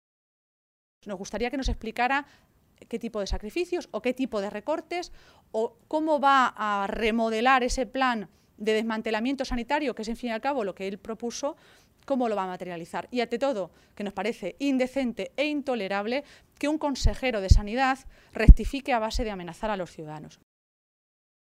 Así lo señalaba Maestre en una comparecencia ante los medios de comunicación, en Toledo, esta tarde, minutos antes de la reunión de la dirección regional del PSOE castellano-manchego.